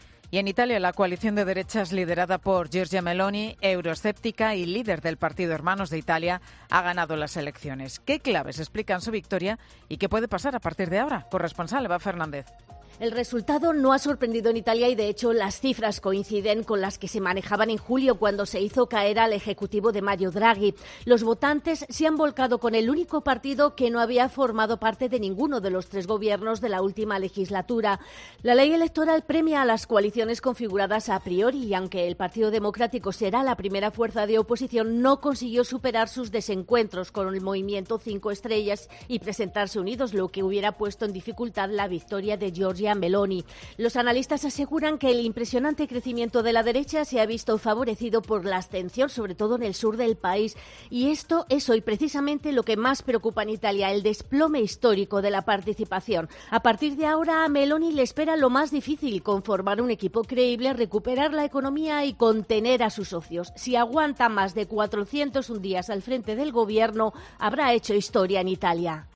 Las claves a las que se enfrente Giorgia Meloni a partir de hoy. Crónica